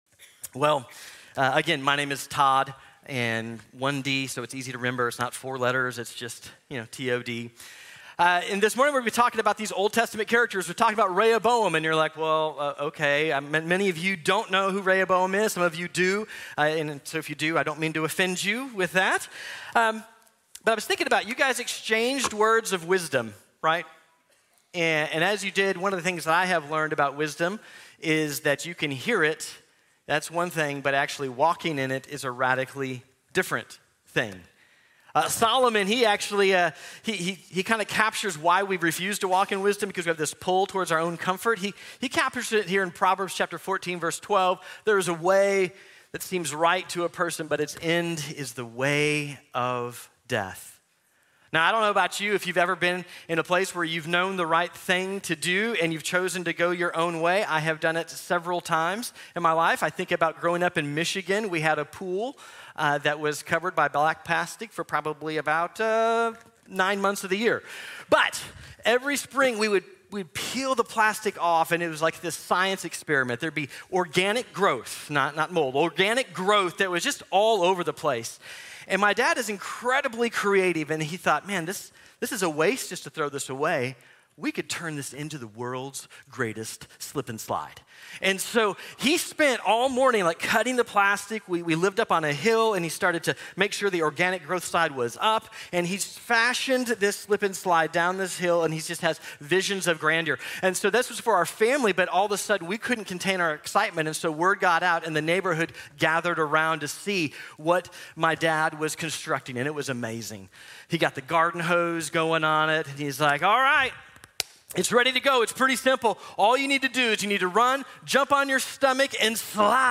Going Our Own Way | Sermon | Grace Bible Church